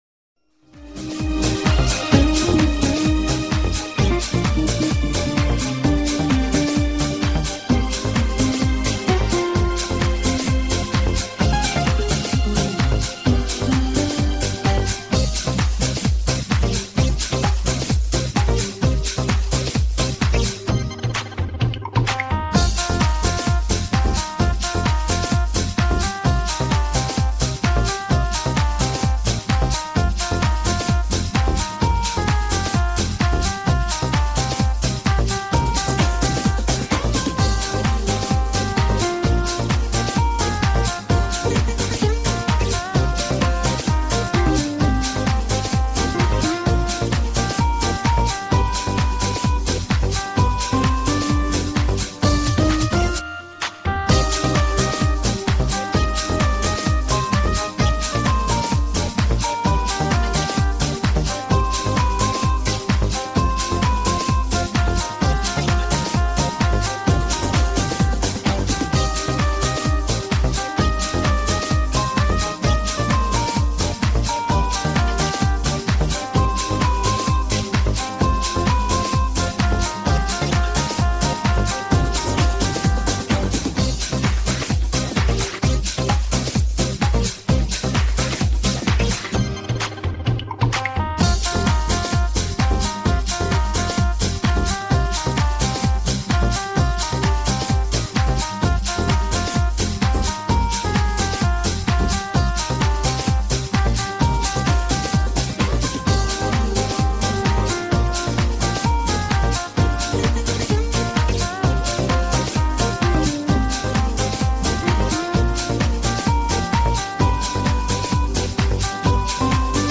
Главная » Файлы » Минусовки » минусы Қазақша